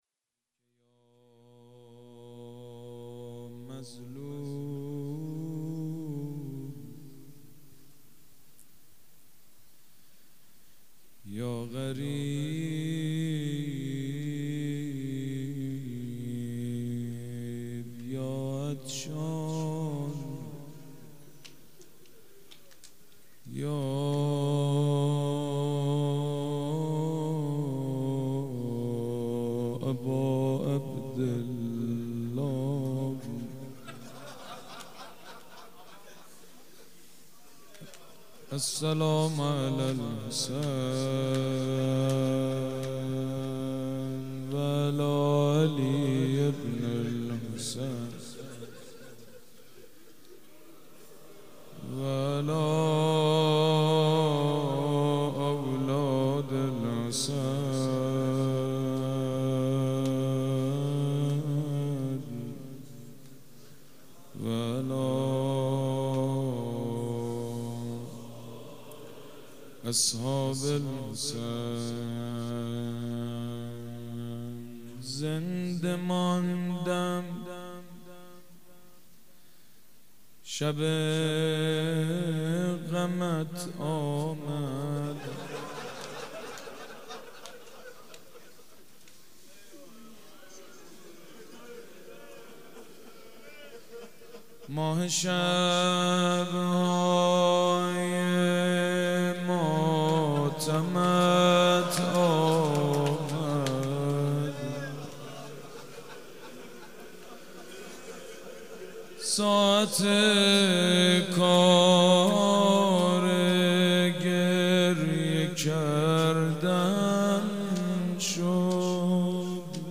سیدمجید بنی‌فاطمه مداح
مناسبت : شب اول محرم